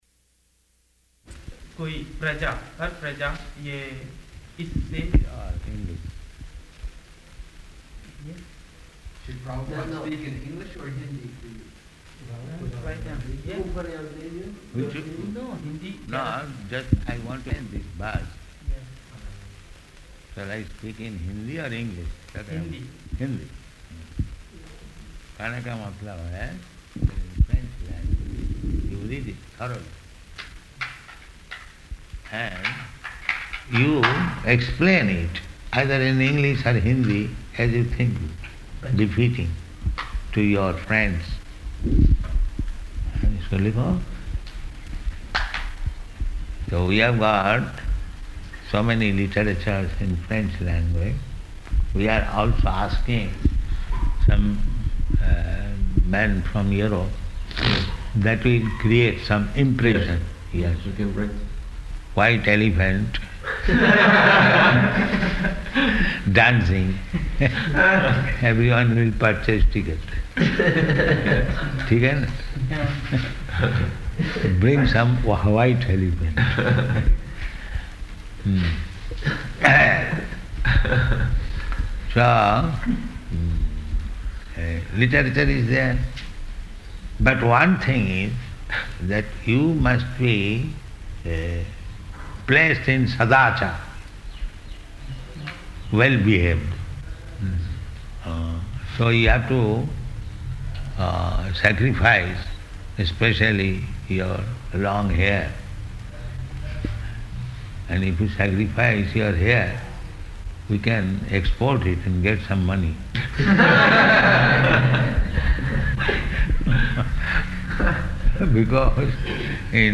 Room Conversation
Room Conversation --:-- --:-- Type: Conversation Dated: October 5th 1975 Location: Mauritius Audio file: 751005R1.MAU.mp3 Guest (1) [Indian man]: [Hindi] Prabhupāda: English.